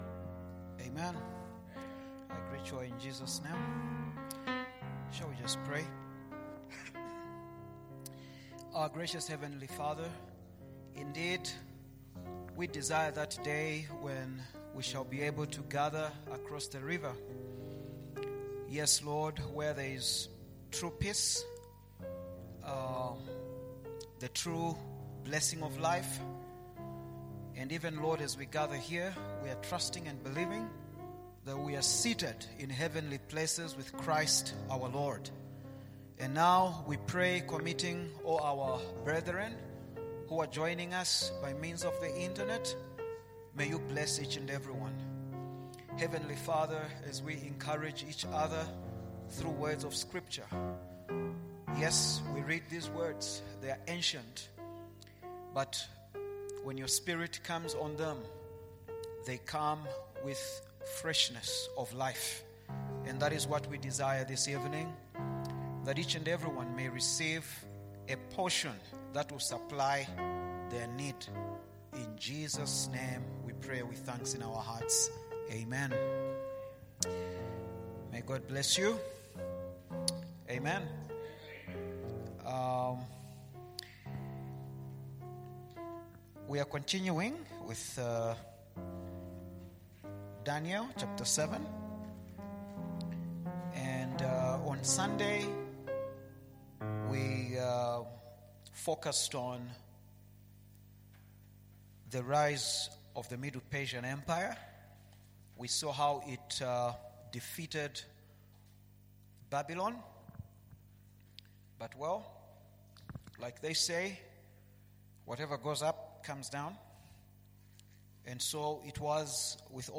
Midweek service